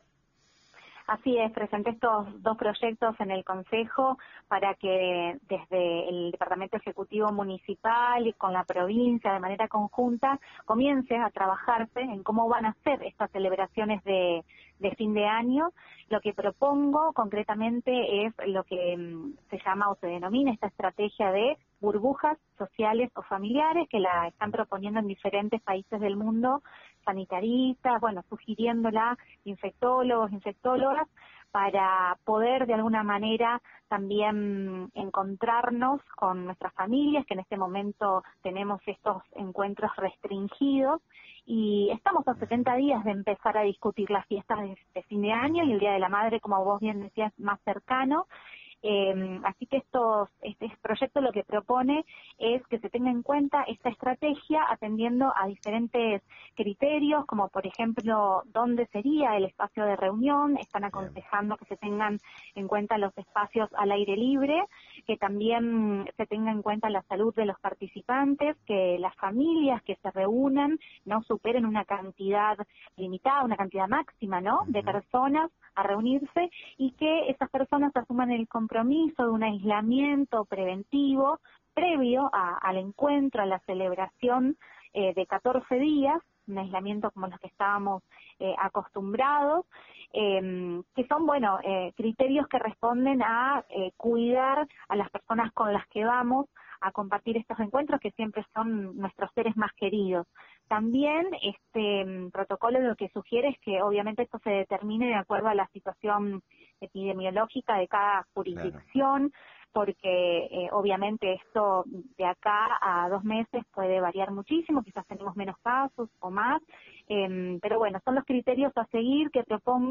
La palabra de la Concejala Valeria López Delzar: